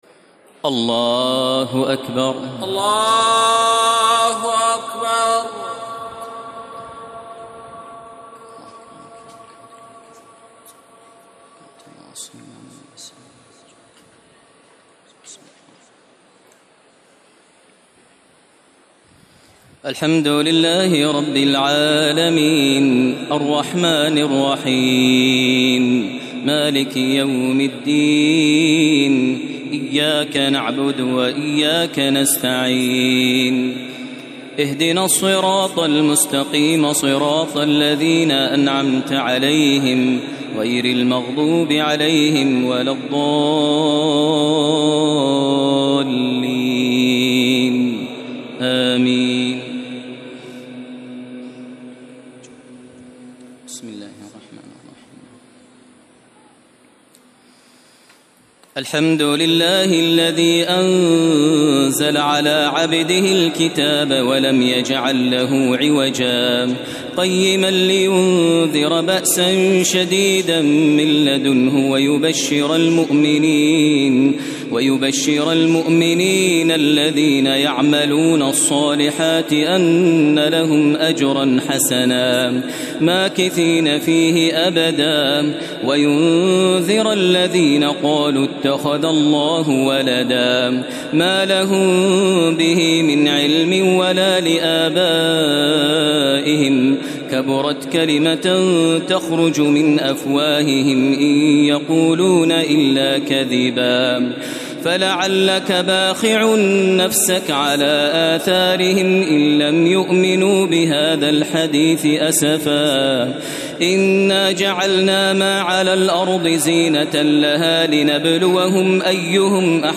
تراويح الليلة الرابعة عشر رمضان 1432هـ من سورة الكهف (1-82) Taraweeh 14 st night Ramadan 1432H from Surah Al-Kahf > تراويح الحرم المكي عام 1432 🕋 > التراويح - تلاوات الحرمين